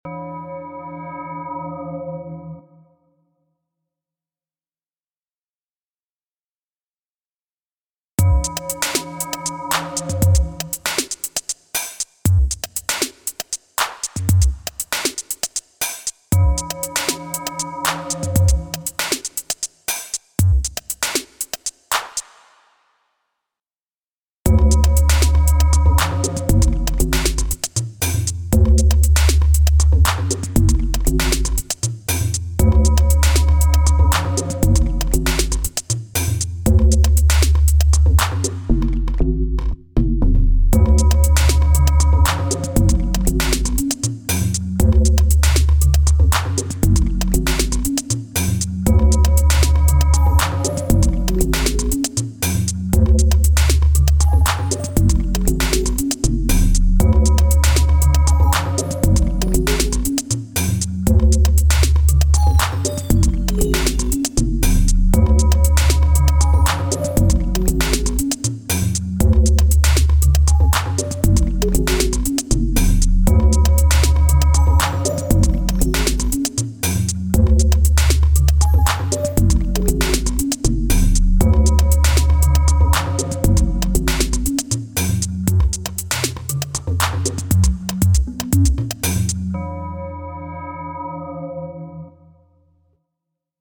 I haven’t been around in a while, been playing bass a lot but haven’t had a chance to sit and make something electronic in a while, here’s a sketch from this afternoon, another bone stock ableton thing.